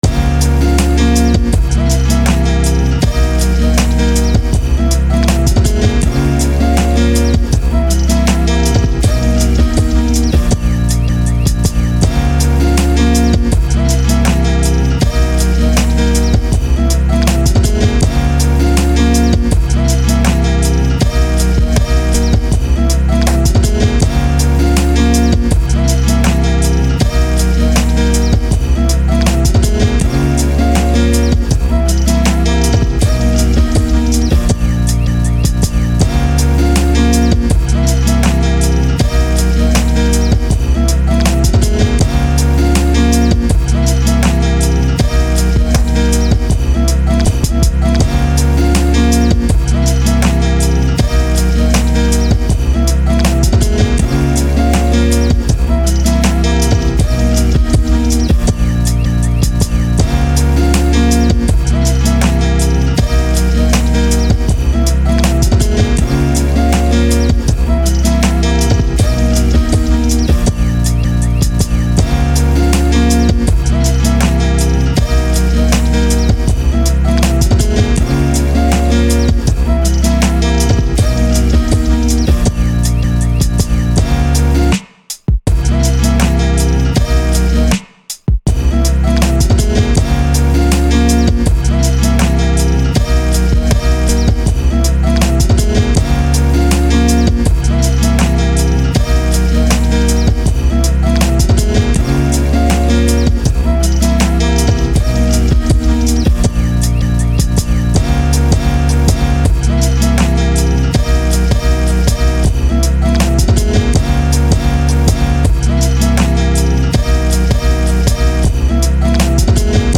[inst.]비가 그칠 때 | 리드머 - 대한민국 힙합/알앤비 미디어
분위기 있고 피아노 소리가 개인적으론 조~금 아쉬워요 ㅠㅠ « Prev List Next »